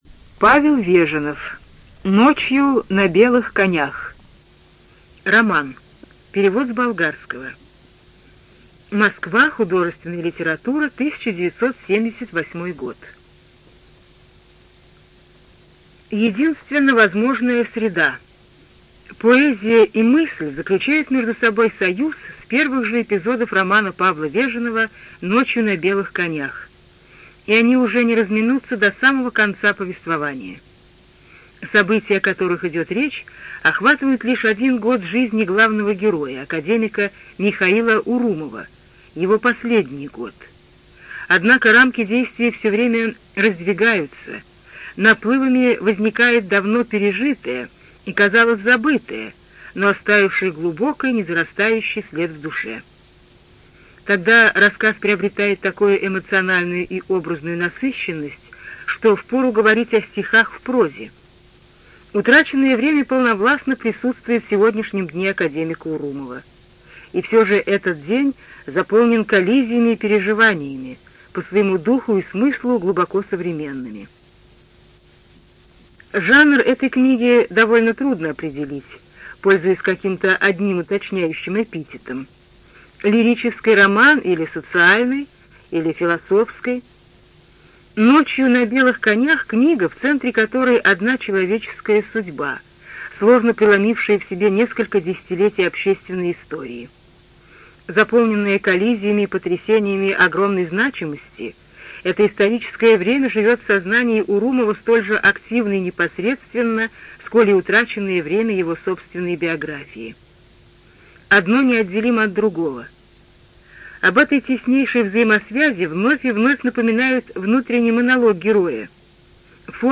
ЖанрСоветская проза
Студия звукозаписиЛогосвос